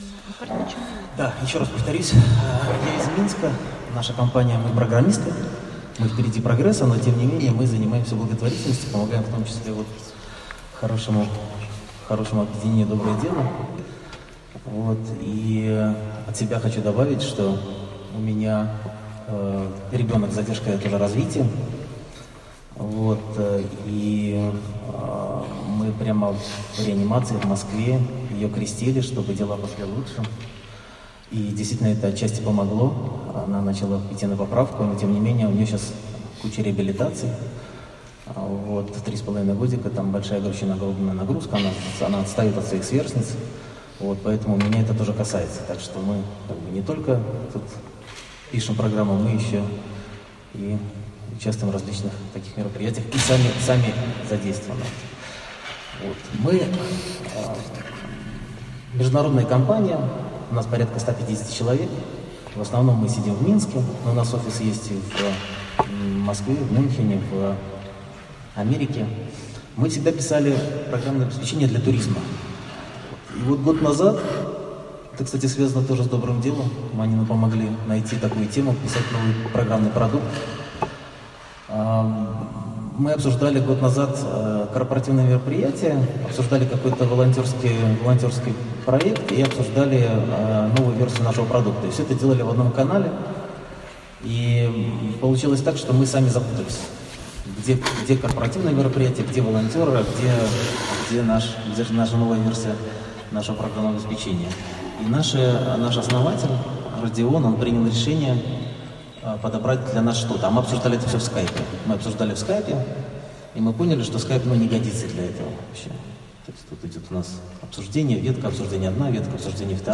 Фрагменты выступлений, День Православного Волонтера в Крефельде, 5-7 июля 2019 - Доброе Дело